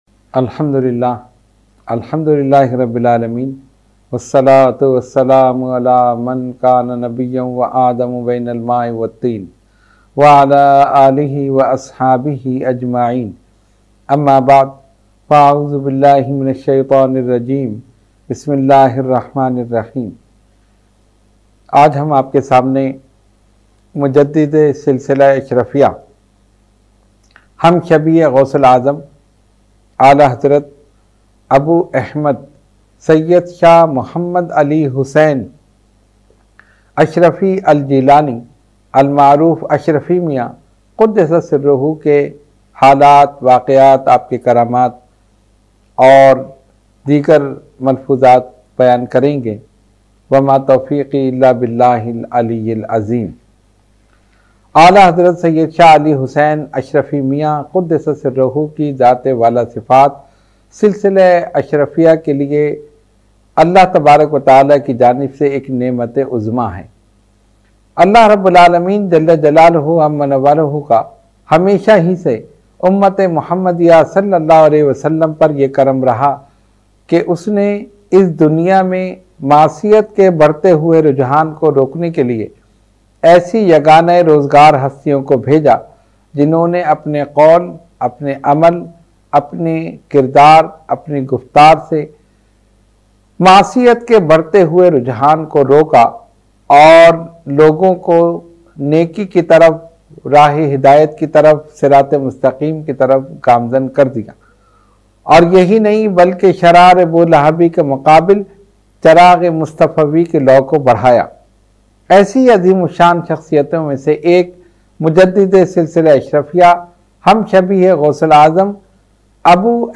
Roohani Tarbiyati Nashist held at Dargah Aliya Ashrafia Ashrafia Ashrafabad Firdous Colony Gulbahar Karachi.
Category : Speech | Language : UrduEvent : Weekly Tarbiyati Nashist